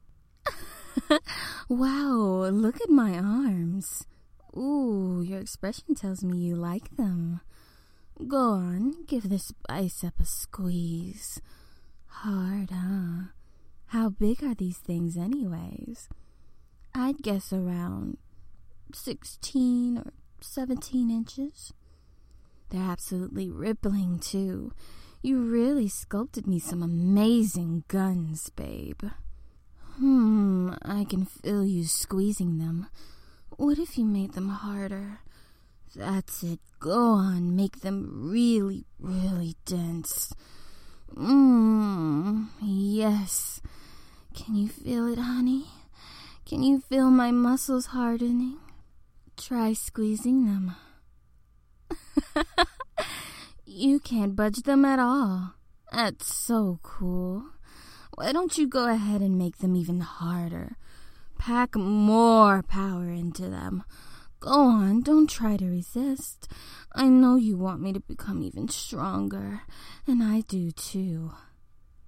Muscle Talk – No Effects Bass Level 1
Muscle-Sample-No-Effects-Bass-1.mp3